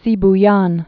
(sēb-yän)